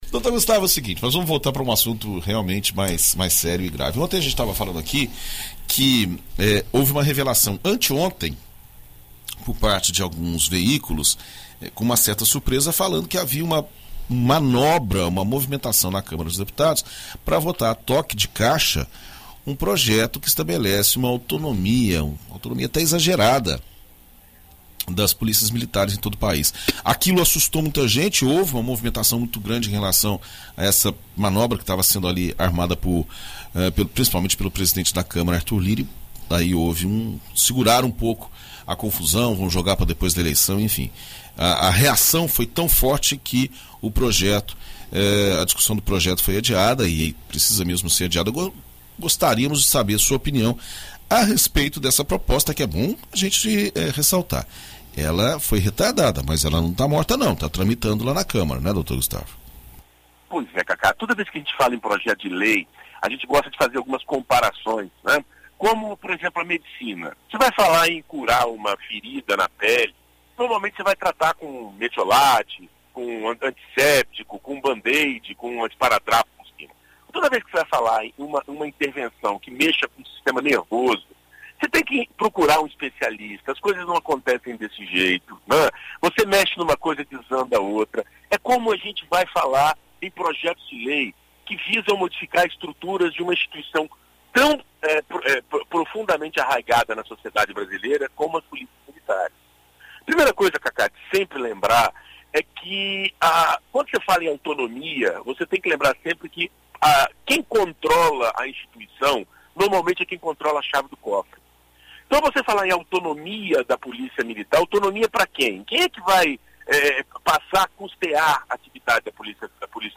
Na coluna Direito para Todos desta quarta-feira (03), na BandNews FM Espírito Santo